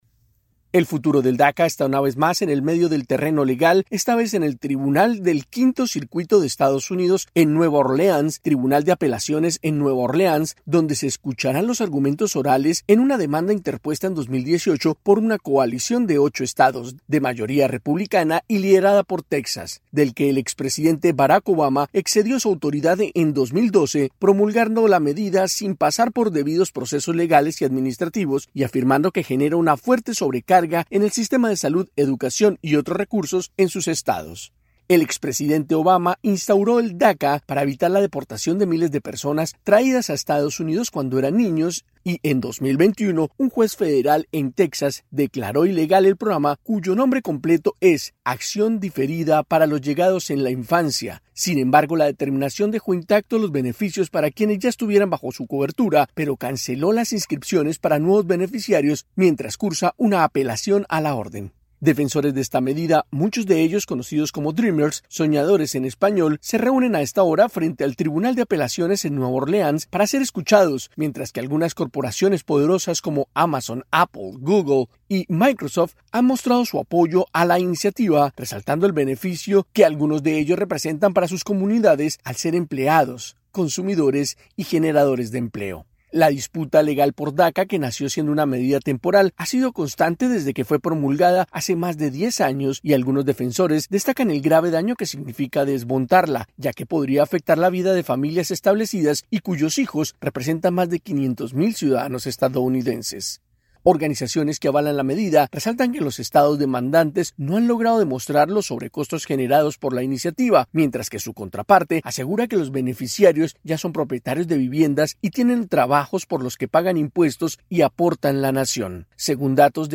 El programa DACA, que favorece a niños traídos a Estados Unidos por sus padres, enfrenta hoy una nueva batalla legal en un tribunal federal de apelaciones en Nueva Orleans. Informa